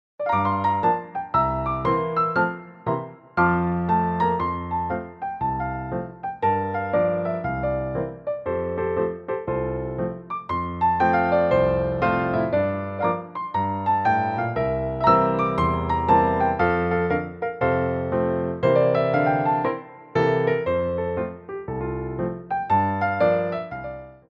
for Ballet Class
Degagés
4/4 (16x8)